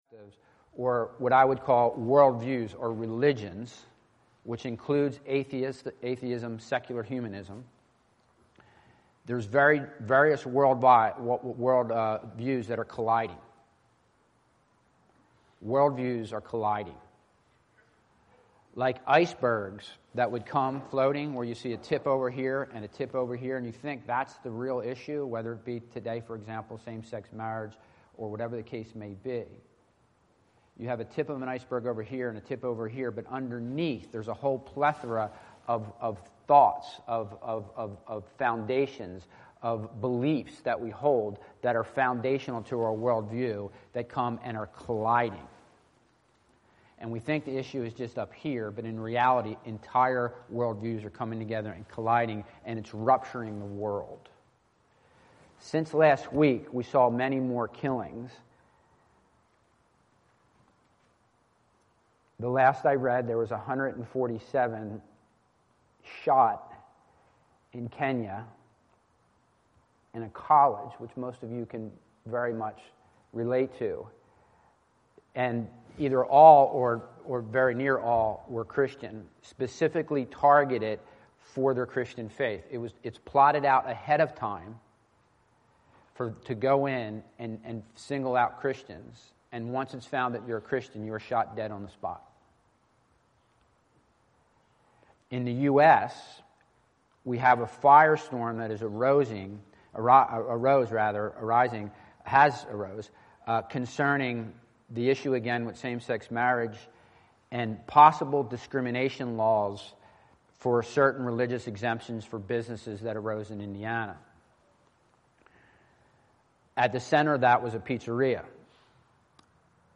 Bible Text: Exodus 12:1-14 | Preacher